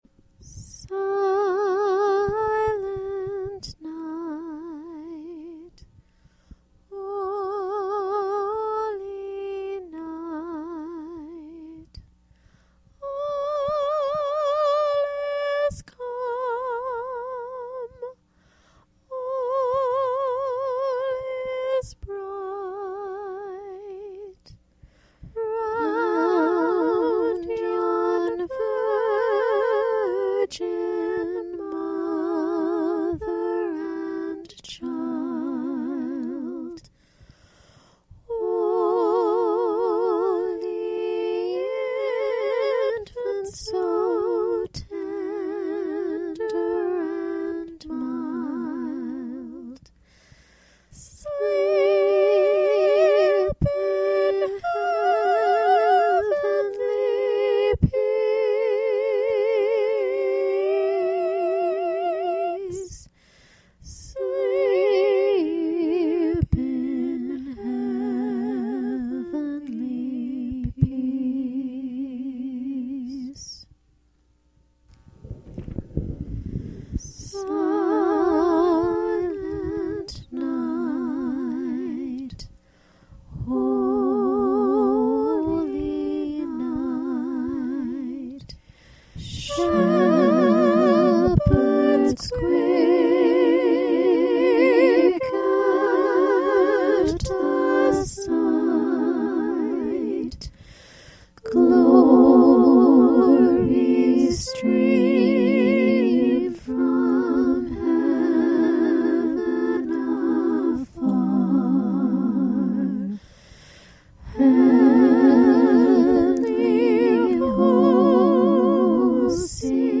The Fifth Annual Blogger Christmalhijrahanukwanzaakah Online Holiday Concert!